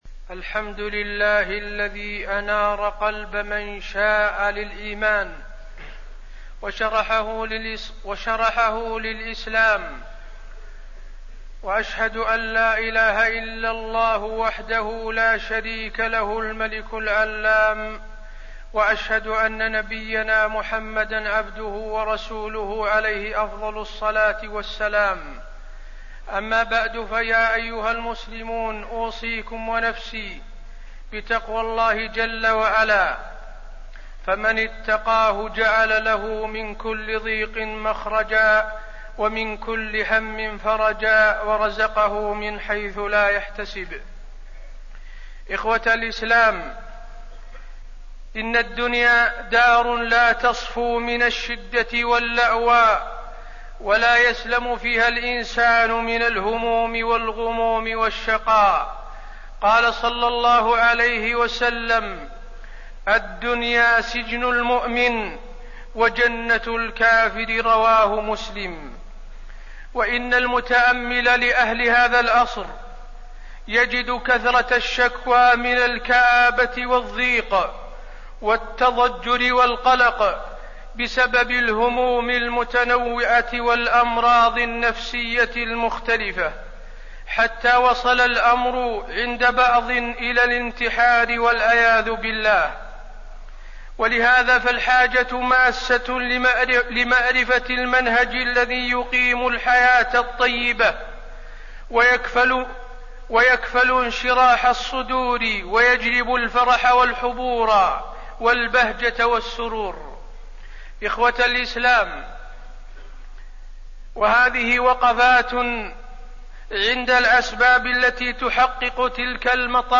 تاريخ النشر ١١ ذو القعدة ١٤٣٠ هـ المكان: المسجد النبوي الشيخ: فضيلة الشيخ د. حسين بن عبدالعزيز آل الشيخ فضيلة الشيخ د. حسين بن عبدالعزيز آل الشيخ الصبر